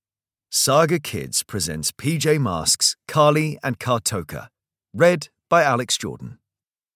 Audio knihaPJ Masks - Carly and Cartoka (EN)
Ukázka z knihy